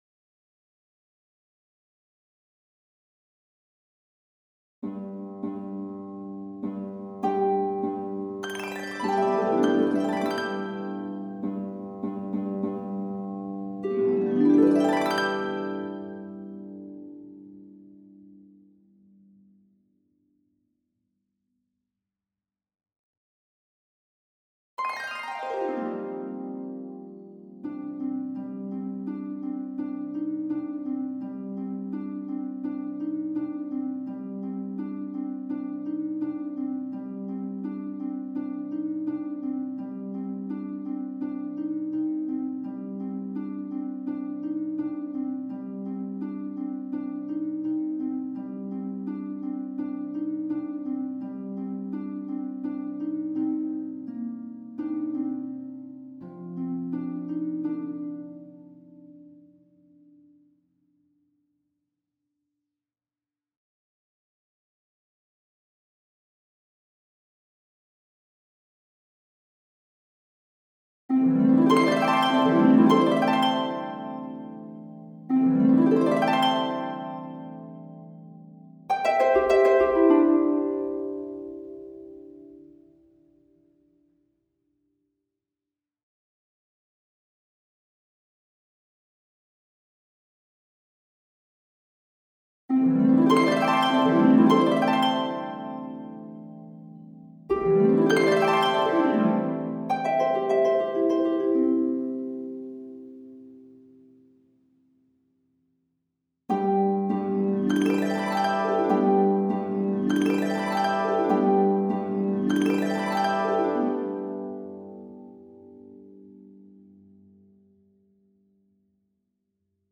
Christ Arose Harp Stem